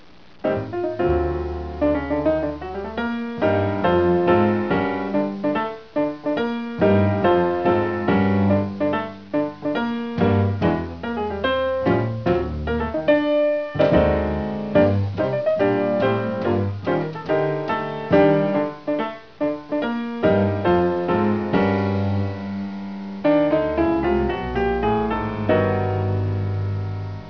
bass
drums
steel-ribbed bass lines